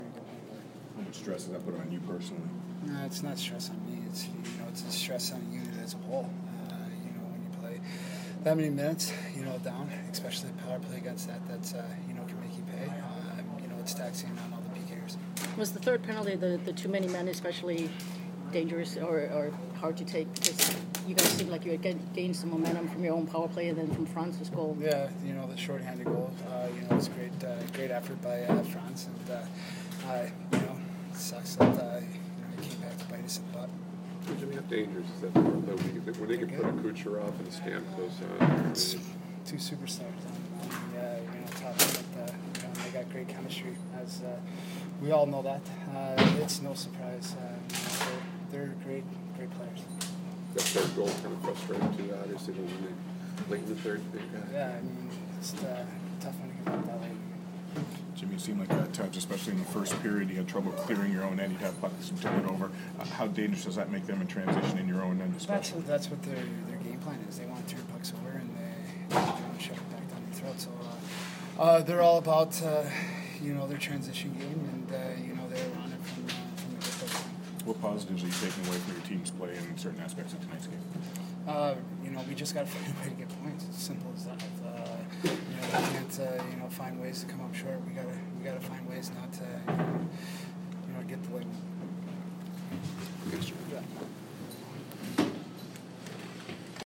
Jimmy Howard Post-Game 10/26